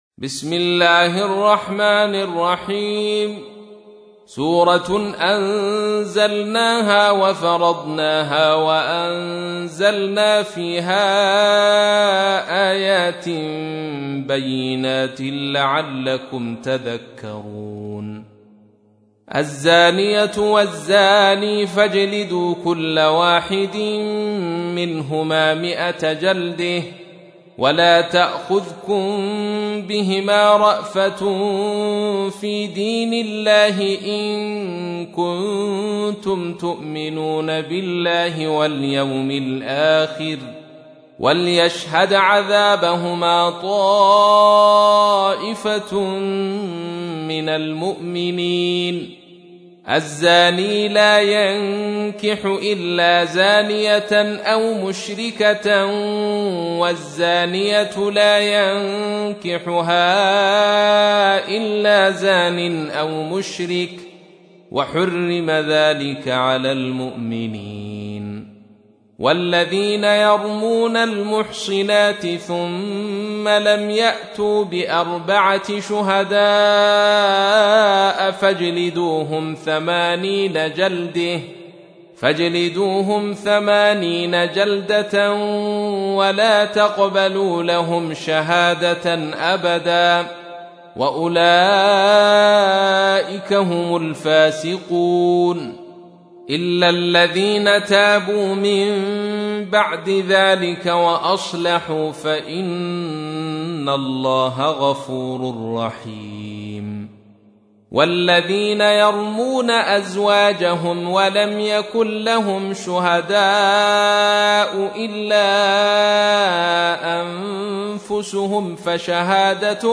تحميل : 24. سورة النور / القارئ عبد الرشيد صوفي / القرآن الكريم / موقع يا حسين